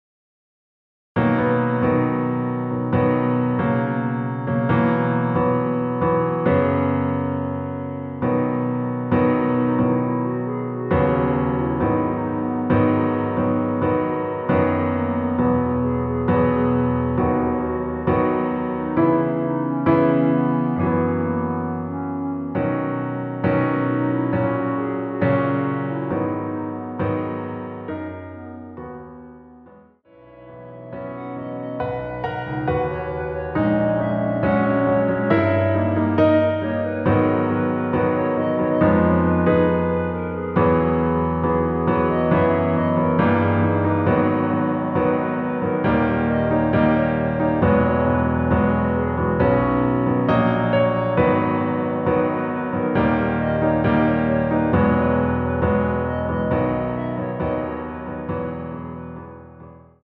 반주를 피아노 하나로 편곡하여 제작하였습니다.
원키에서(-2)내린(Piano Ver.) (1절+후렴)멜로디 포함된 MR입니다.